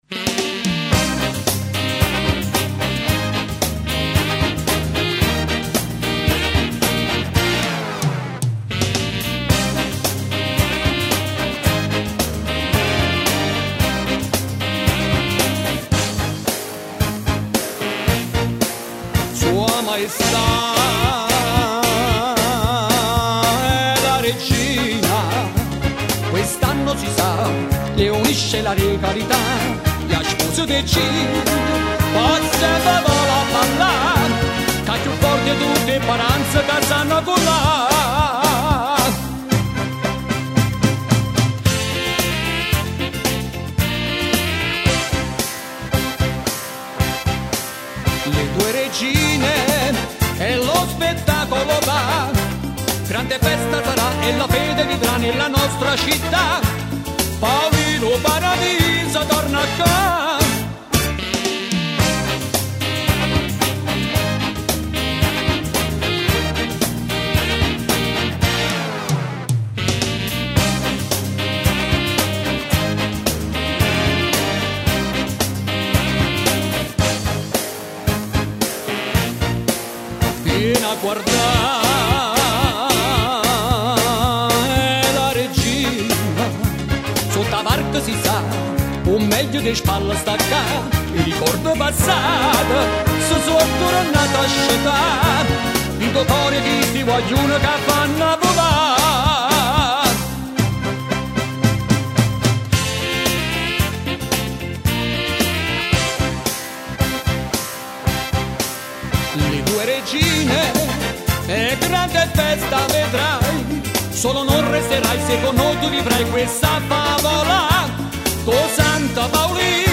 Divisione Musicale: De Angelis Band